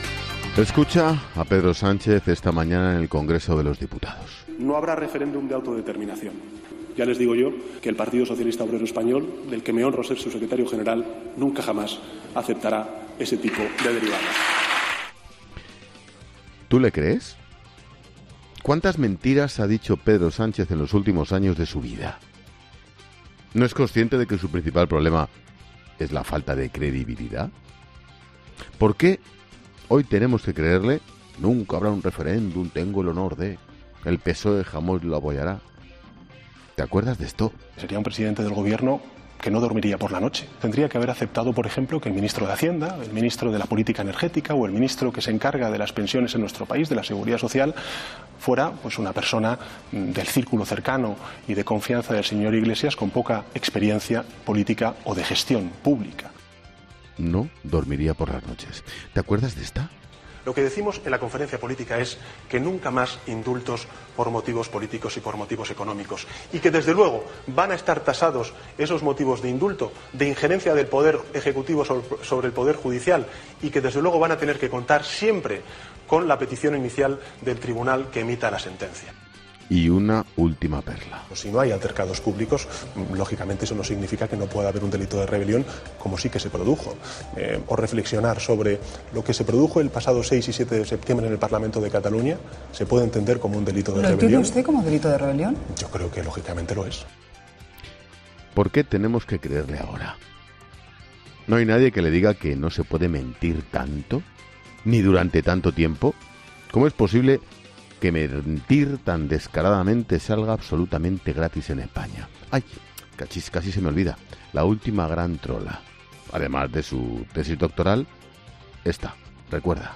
Monólogo de Expósito
El director de 'La Linterna', Ángel Expósito, reflexiona sobre las últimas declaraciones de Sánchez